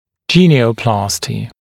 [‘ʤiːnɪəuˌplɑːstɪ][‘джи:ниоуˌпла:сти]гениопластика, остеотомия подборочного отдела нижней челюсти